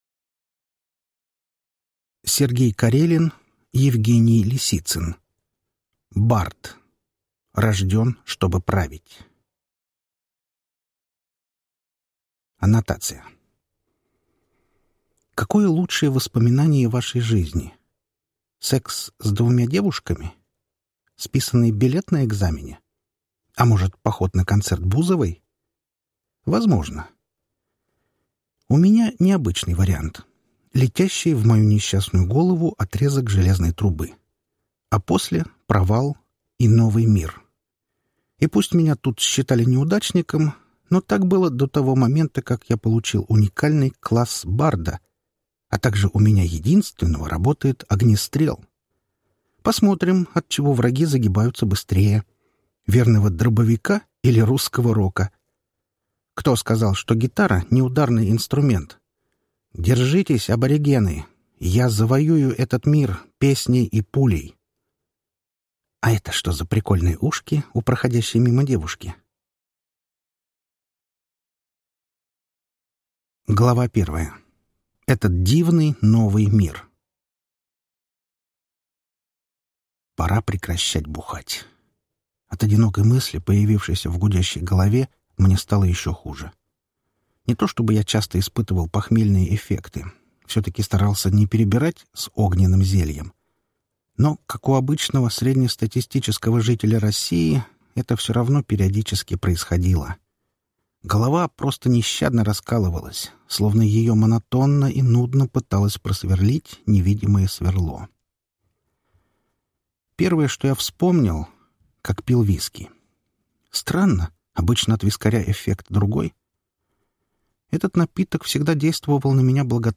Аудиокнига Бард 1 | Библиотека аудиокниг